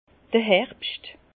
Ville Prononciation 67 Herrlisheim